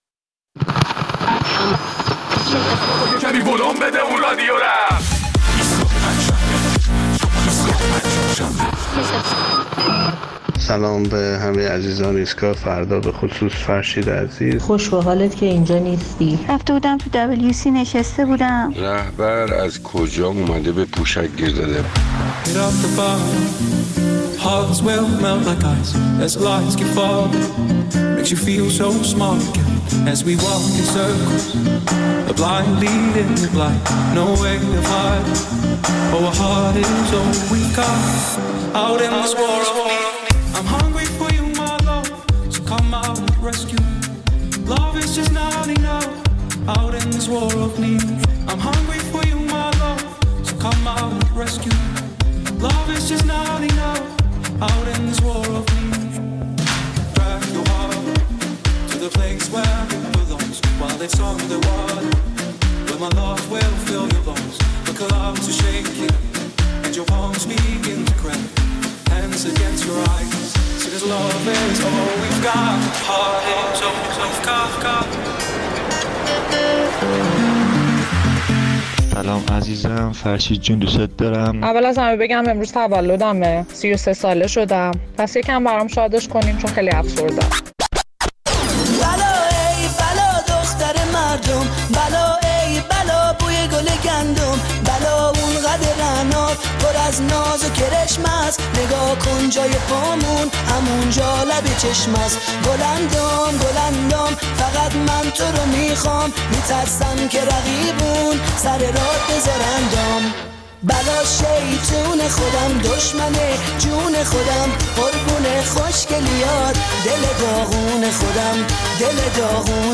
در این برنامه ادامه نظرات شنوندگان را در مورد صحبت‌های رهبر ایران درباره تاثیر خرابکاری دشمنان در کمبود پوشک در کشور می‌شنویم.